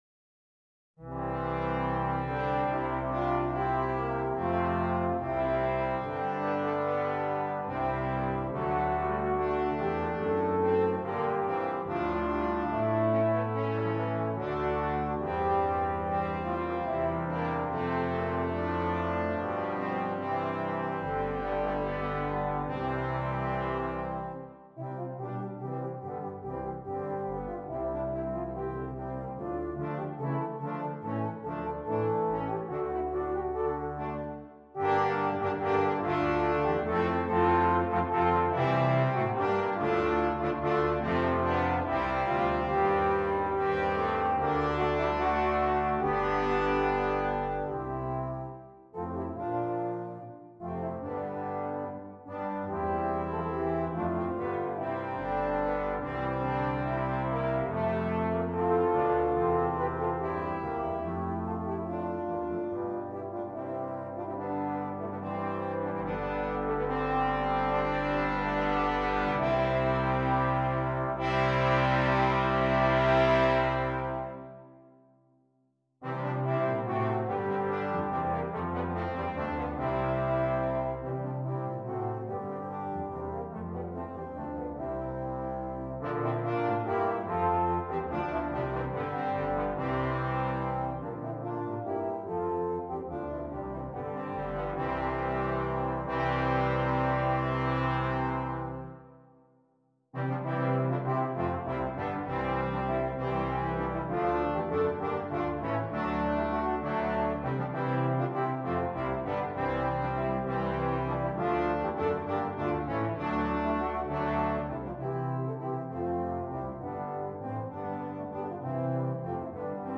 2 Horns, 2 Trombones, Tuba